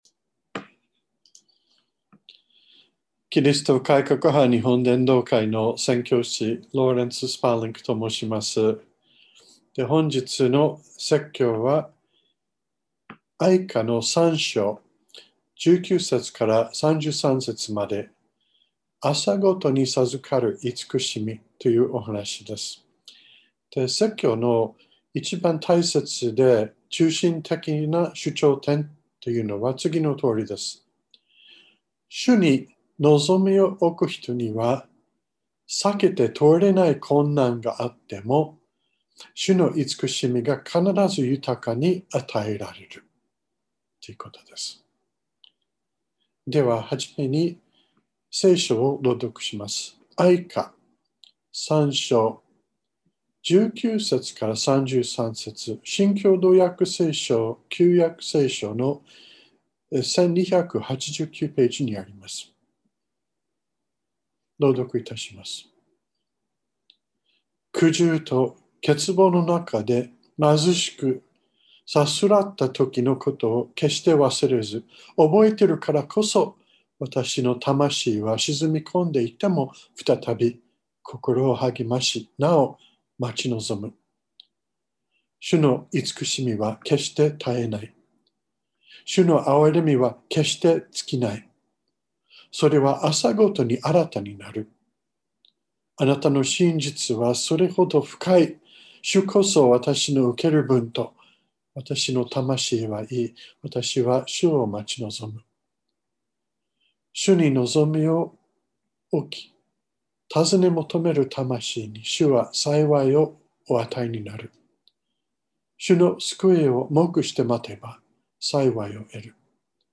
2021年01月24日朝の礼拝「朝ごとに授かるいつくしみ」川越教会
説教アーカイブ。